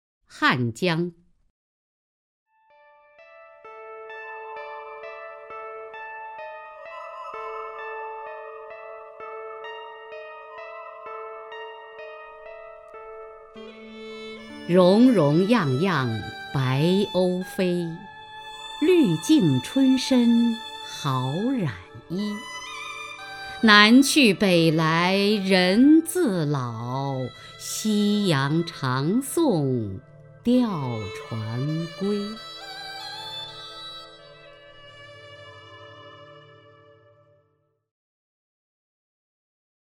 雅坤朗诵：《汉江》(（唐）杜牧) (右击另存下载) 溶溶漾漾白鸥飞， 绿净春深好染衣。
名家朗诵欣赏